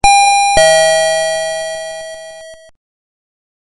01. Door Bell I
• Dual tone melody
• 3 kinds of songs (Ding-Dong, Ding-Dong/Ding-Dong and Westminster chime)